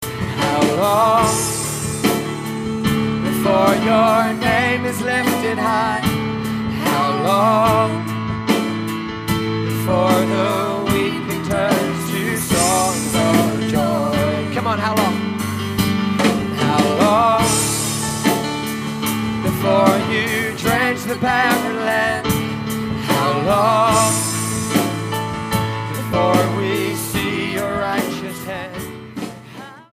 STYLE: MOR / Soft Pop
accompanied by a drummer and backing vocalist
piano-backed powerful and uplifting prayer ministry